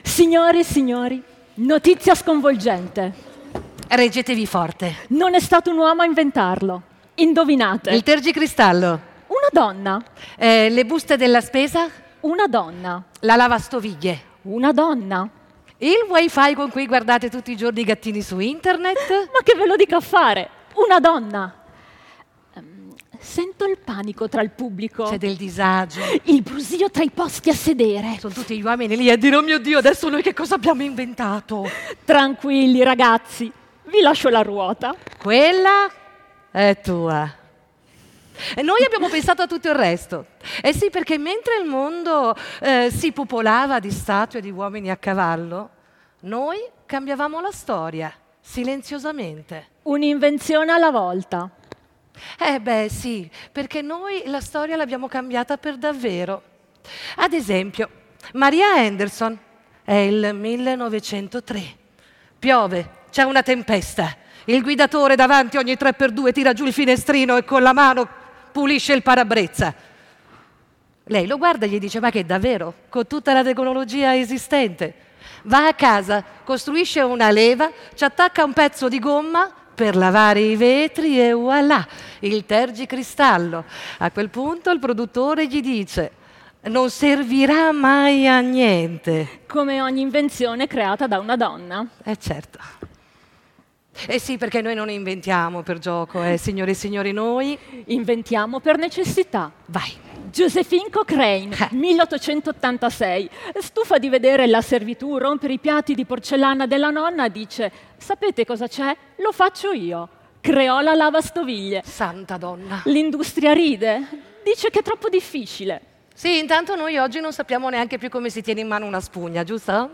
L’Associazione ‘Non è Colpa Mia’ ha organizzato l’evento Tutte figlie di Eva che si è tenuto domenica 23 marzo presso il Crogiolo Marazzi, nell’ambito delle iniziative organizzate dall’Unione dei Comuni del Distretto Ceramico in occasione della Festa della Donna, con il patrocinio del Comune di Sassuolo.